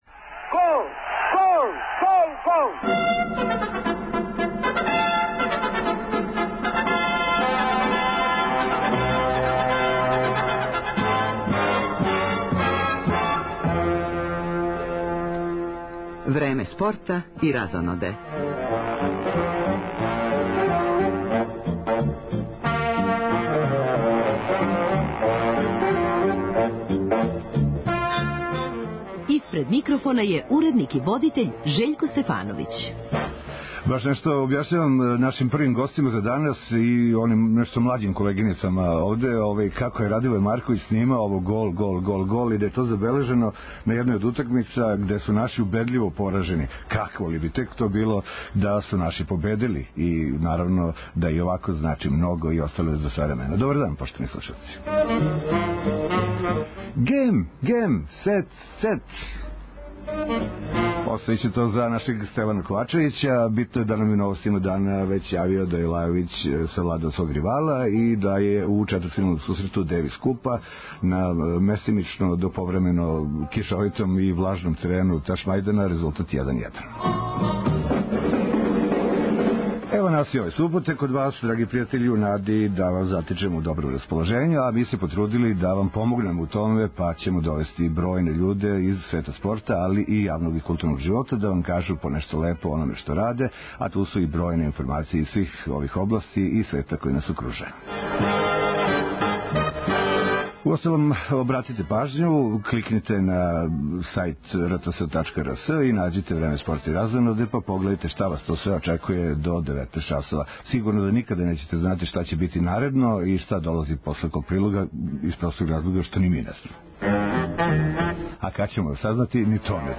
Репортери Радио Београда 1, током емисије, извештаваће о кретању резултата у мечу дублова четвртфиналног сусрета Девис купа, између Србије и Велике Британије - са једне стране мреже требало би да буду Ненад Зимоњић и Јанко Типсаревић, с друге Џејми Мари и Доминик Инглот. Говоримо о бициклистичкој акцији `Продужи живот`, у којој учествују рекреативци и хуманитарци, који тренутно возе кроз Немачку.